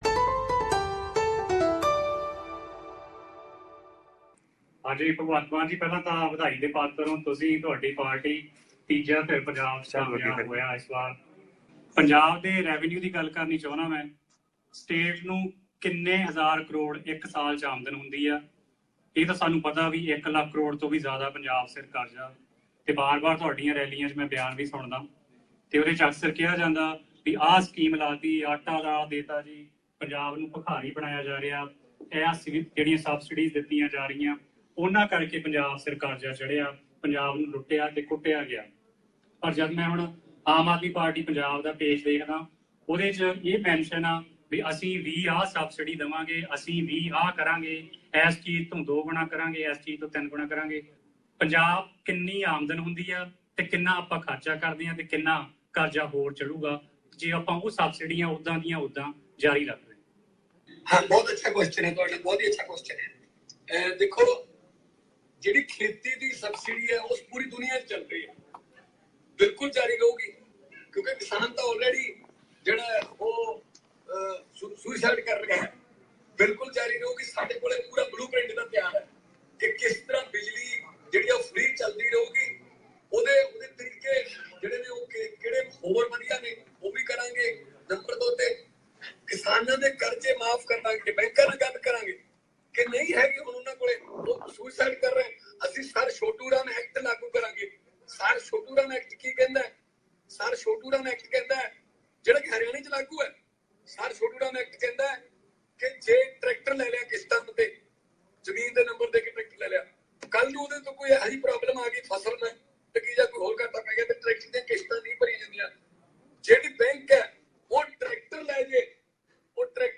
While speaking to Melbourne based NRI gathering via teleconference, MP Bhagwant Mann said that some of the important subsidies will continue if their party forms a government in Punjab. While answering a question asked about their party’s plans to revive Punjab’s budget, Mr Mann said that the subsidies on agriculture, electricity and atta-daal will continue, and the party will also revise the excise policy, if in Government.